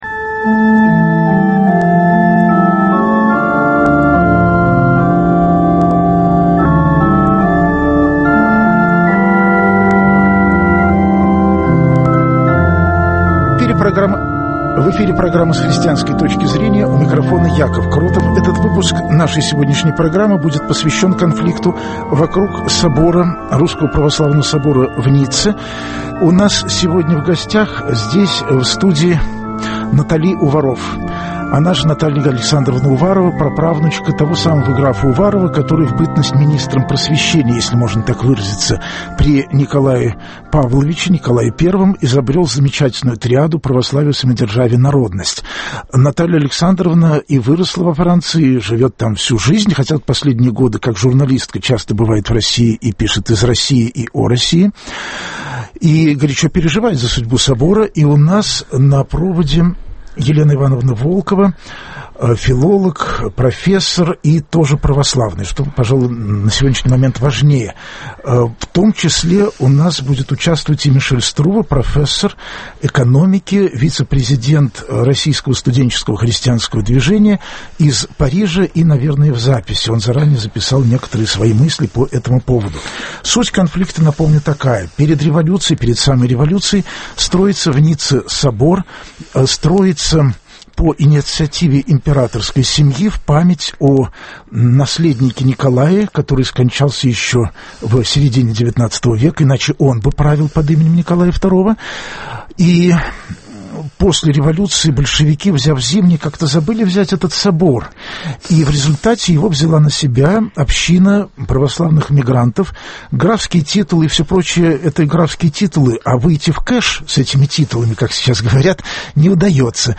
слушают друг друга верующие и неверующие